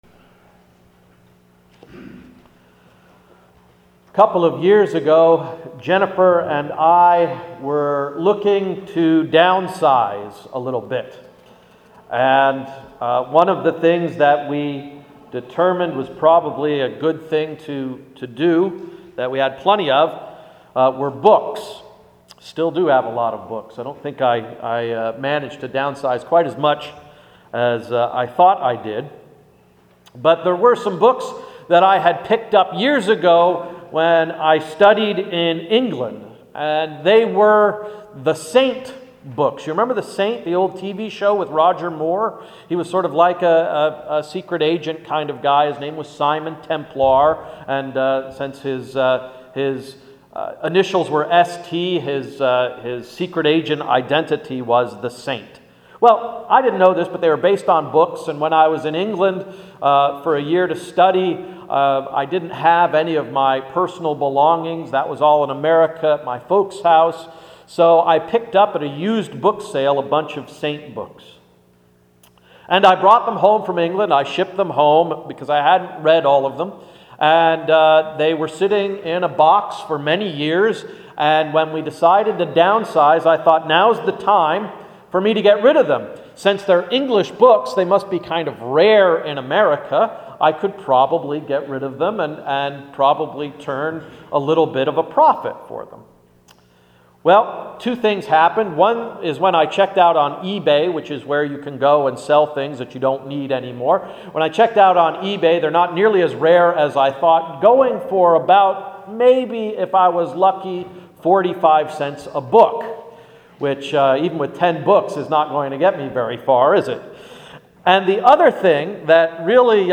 Sermon of November 14–“Mapping the Mountain” Isaiah 65:11-25 – Emmanuel Reformed Church of the United Church of Christ